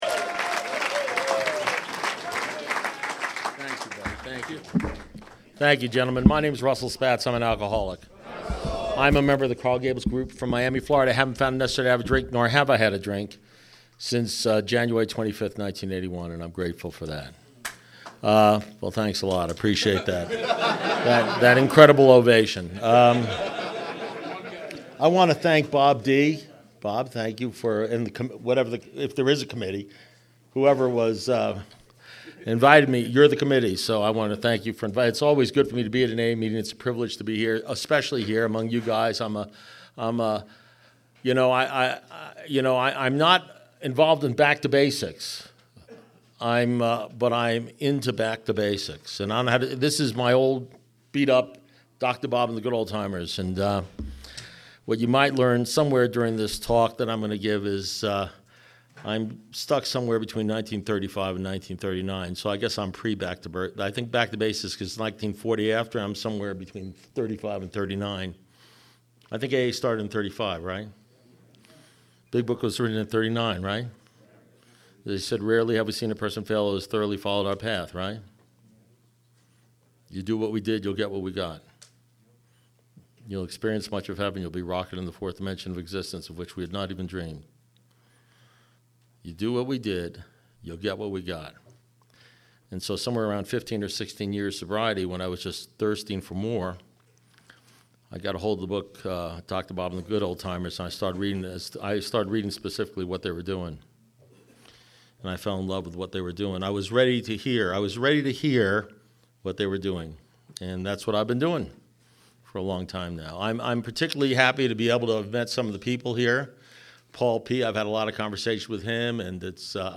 Alcoholics Anonymous Speaker Recordings
at Men’s Back to Basics retreat in California, October 2015